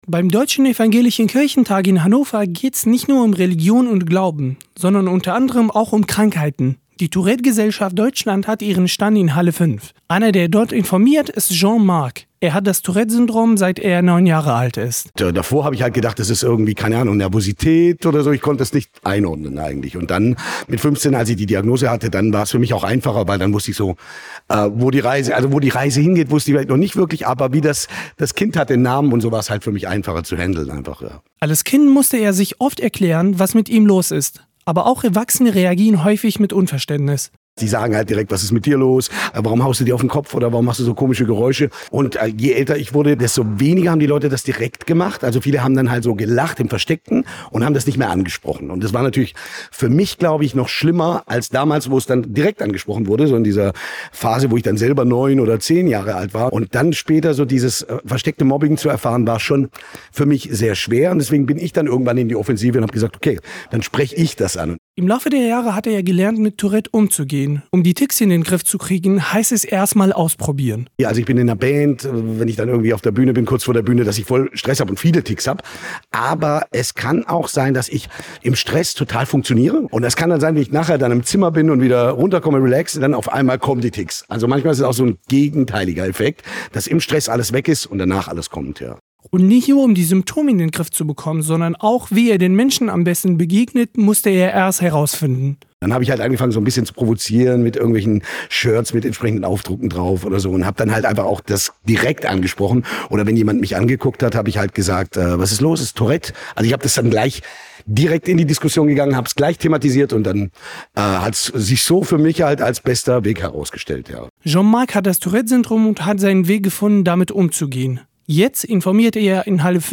Die TGD auf dem Evangelischen Kirchentag
Der Deutsche Evangelische Kirchentag 2025 fand vom 30. April bis zum 4. Mai 2025 in Hannover statt.